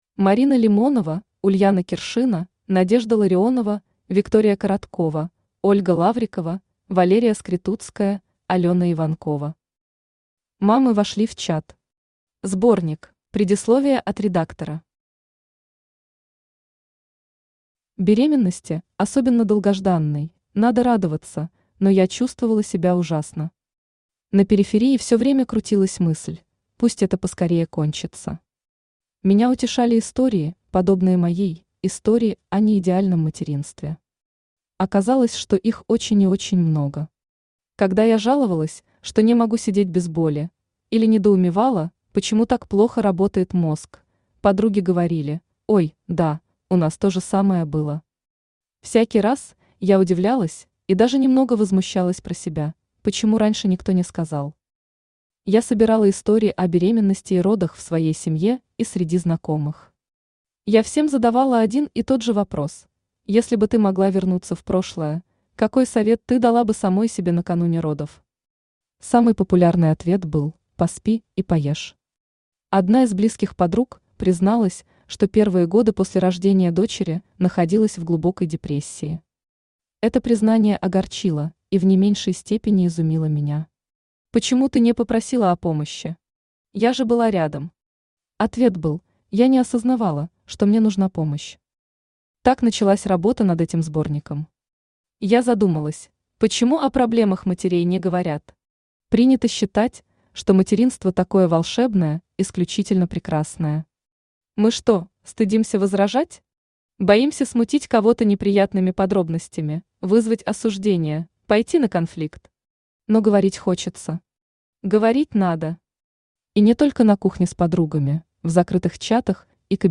Аудиокнига Мамы вошли в чат. Сборник | Библиотека аудиокниг
Сборник Автор Ульяна Киршина Читает аудиокнигу Авточтец ЛитРес.